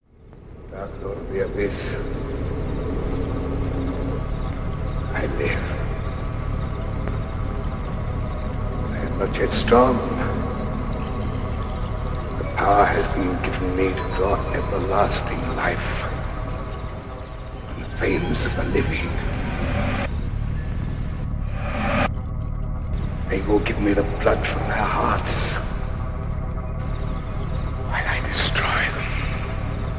Halloween movie soundscape